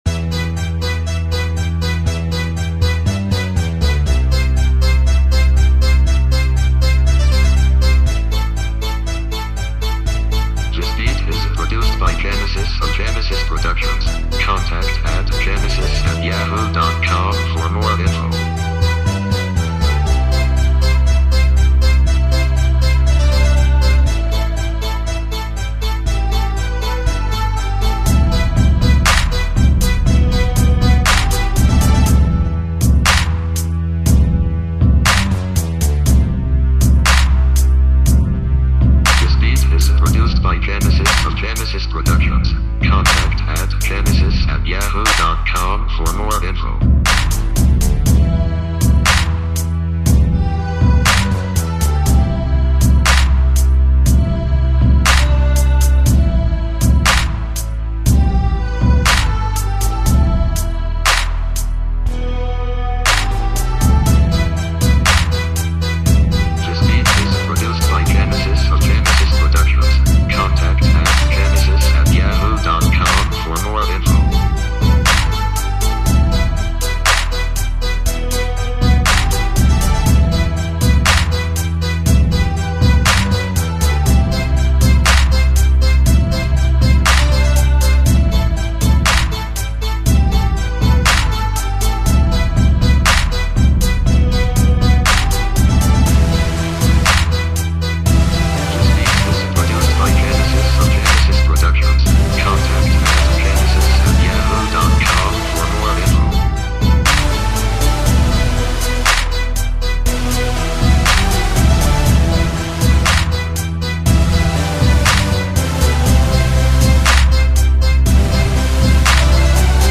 R&B Instrumentals